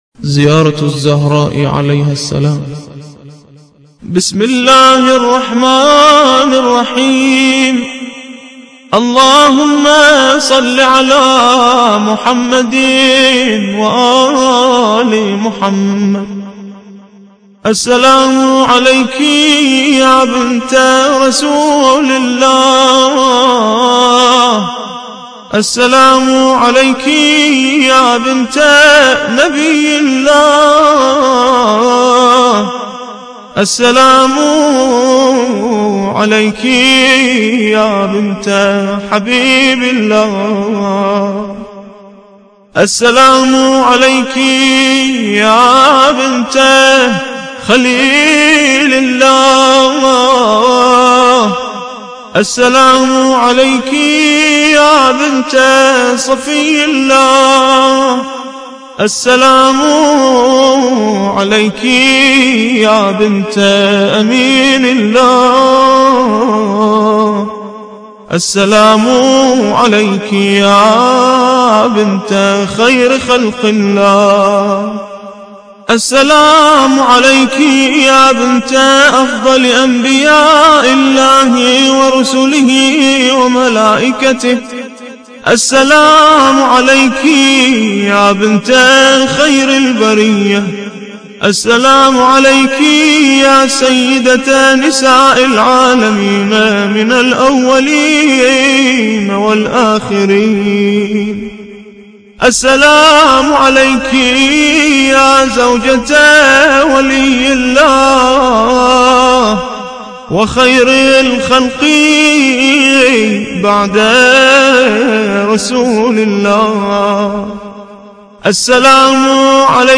زيارة الزهراء عليها السلام – الرادود